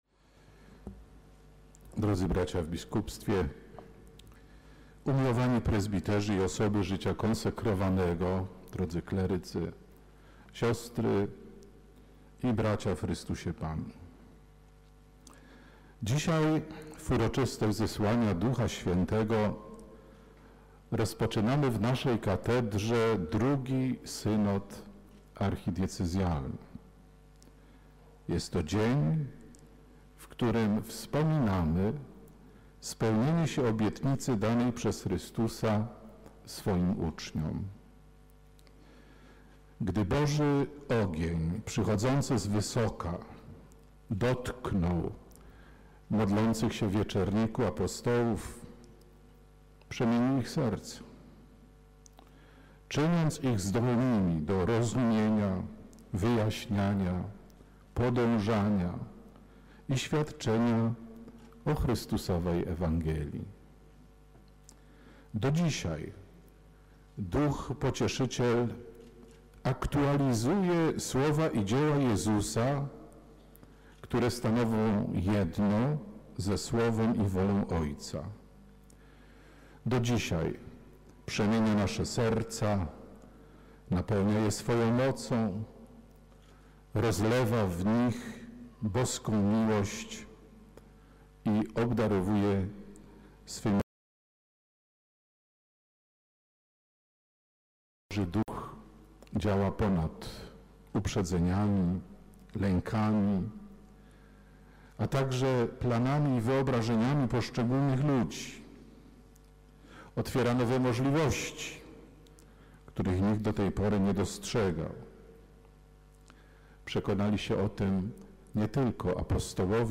Mszy Św. w Katedrze Wrocławskiej przewodniczył metropolita wrocławski, ksiądz arcybiskup Józef Kupny.
Ksiądz arcybiskup wygłosił słowo do wiernych, podsumowując ostatnie miesiące pracy w parafiach, dekanatach, zespołach presynodalnych oraz w sekretariacie synodu. Zwrócił uwagę na najważniejsze cele Synodu Archidiecezji Wrocławskiej, a także podkreślał rolę wspólnoty, komunii i misji naszego Kościoła.